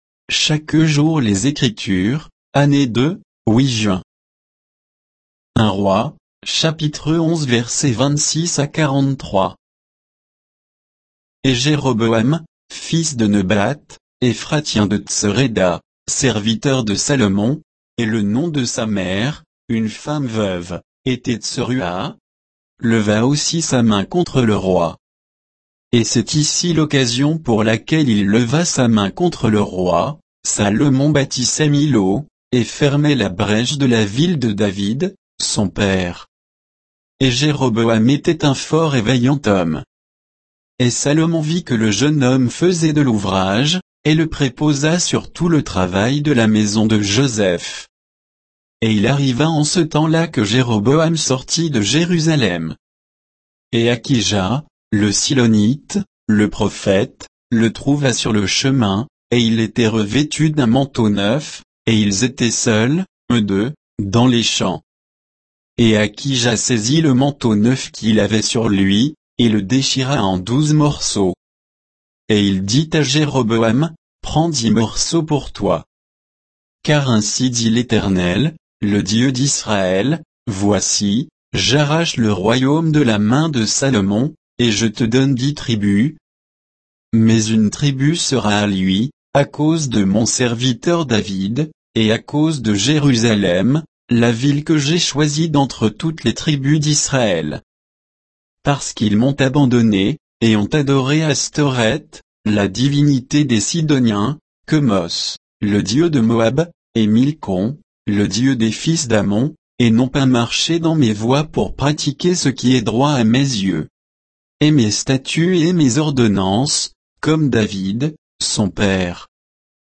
Méditation quoditienne de Chaque jour les Écritures sur 1 Rois 11, 26 à 43